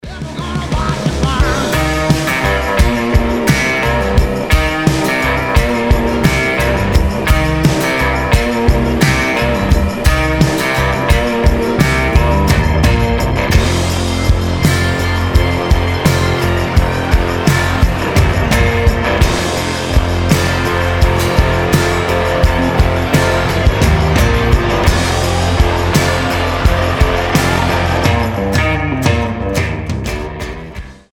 • Качество: 320, Stereo
гитара
country rock
кантри
Шикарная музыка в стиле кантри